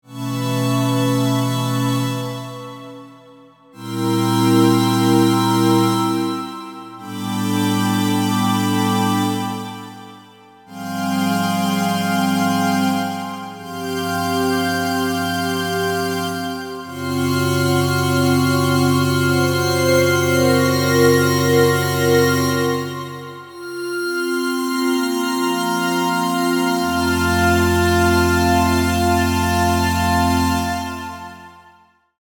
ベル系のパッド音色。